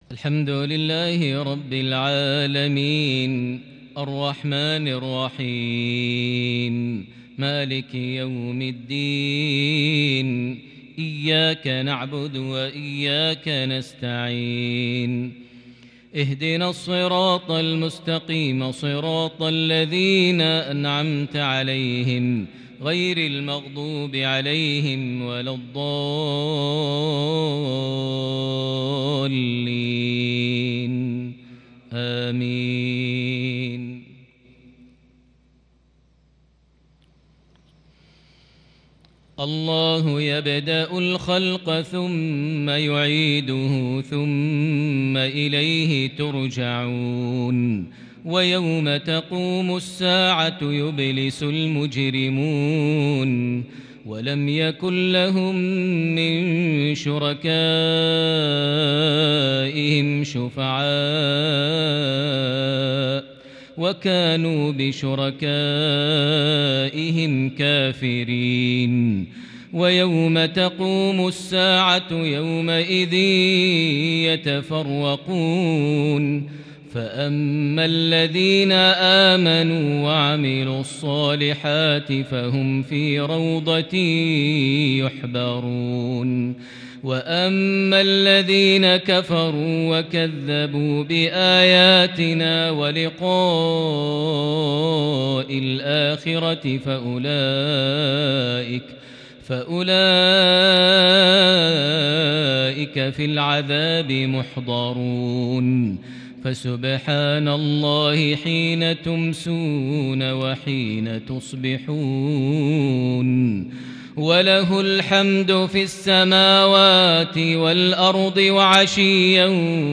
عشائية متألقة بالخشوع فريدة بالكرد من سورة الروم (12-27) | 13 ربيع الثاني 1442هـ > 1442 هـ > الفروض - تلاوات ماهر المعيقلي